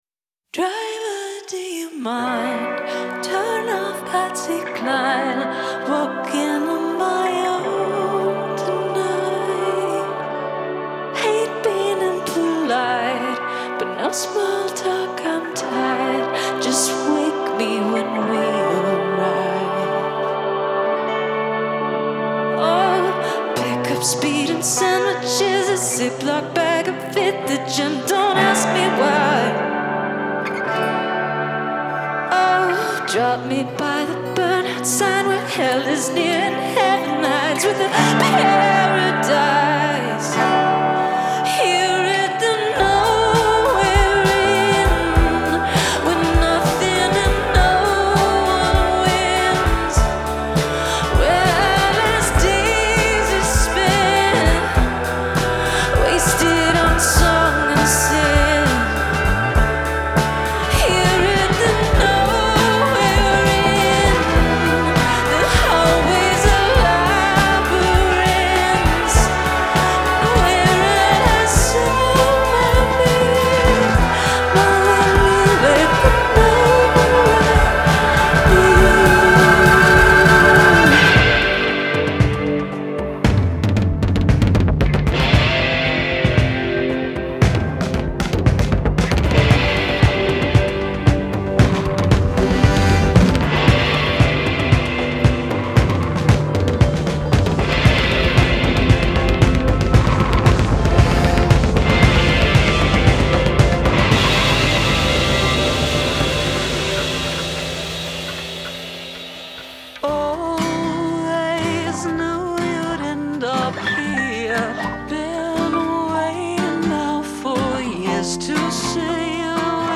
Genre : Bandes originales de films